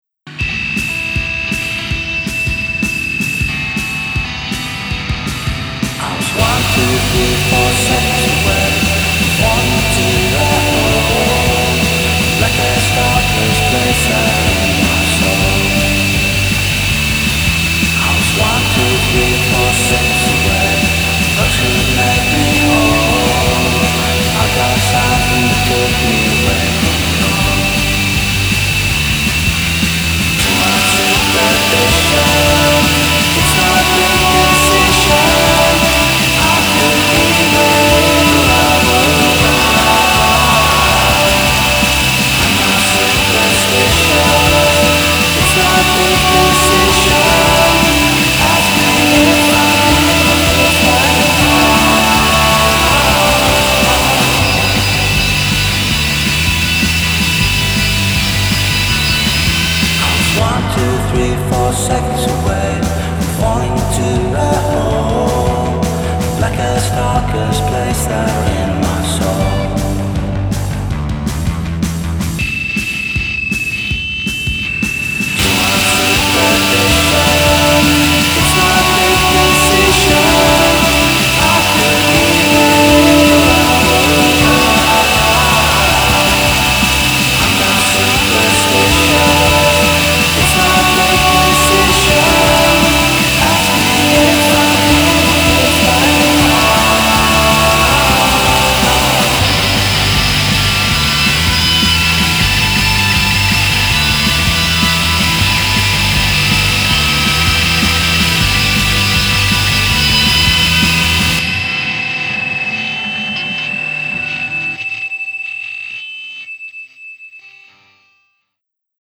the noisiest pop single to ever come out of Sweden